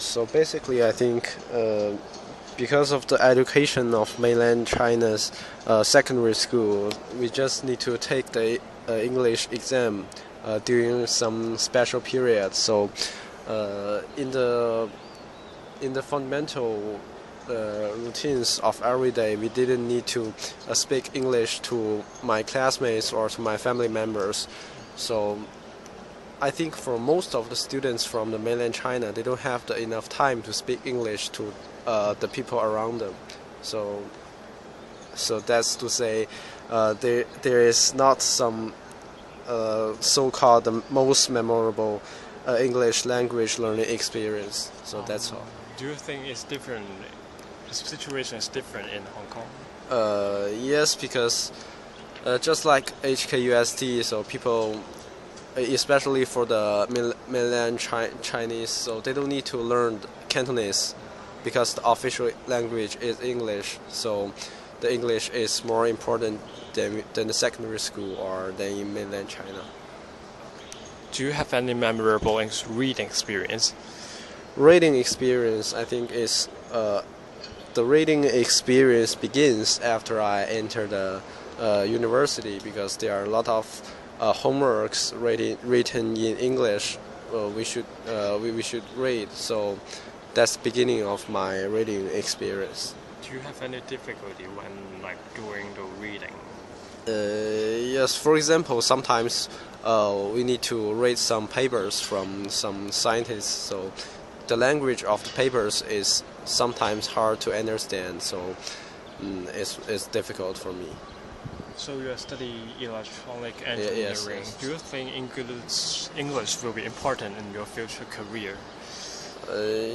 This Engineering major thinks that he didn’t have enough time to speak English in secondary school. He finds language in scientific papers hard to understand.